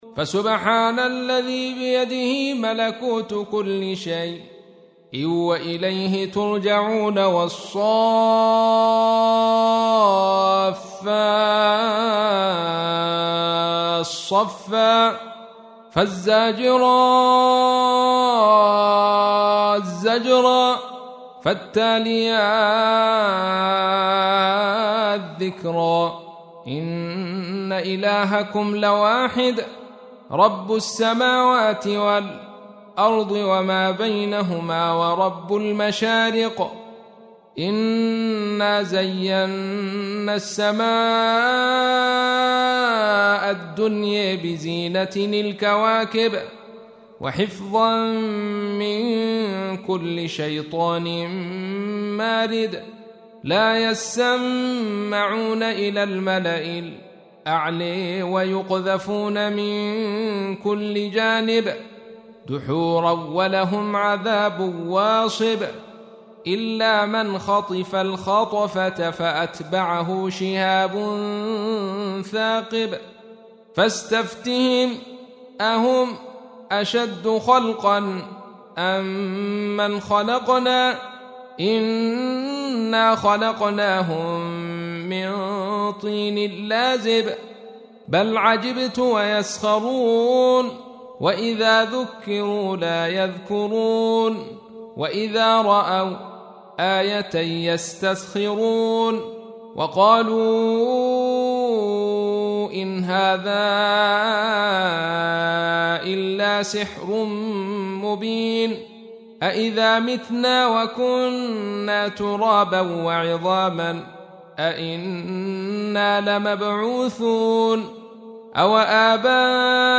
سورة الصافات / القارئ عبد الرشيد صوفي / القرآن الكريم / موقع يا حسين